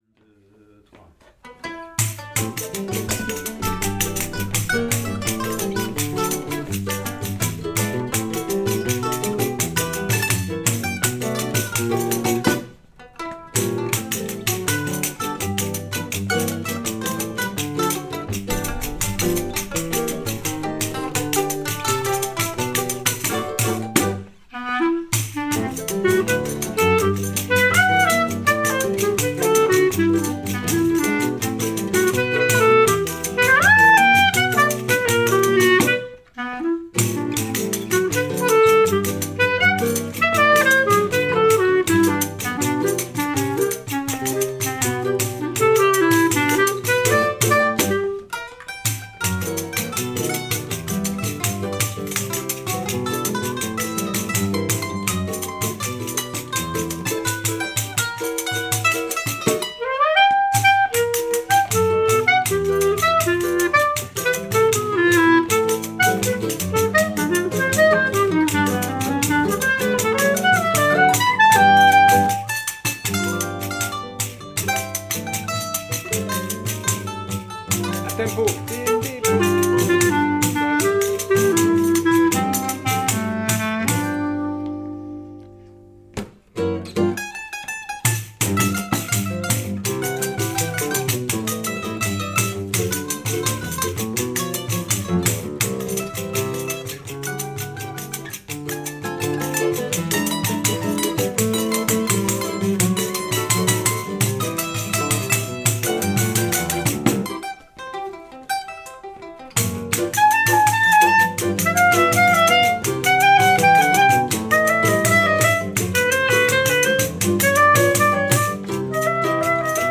Rec atelier
Répétition Novembre 2023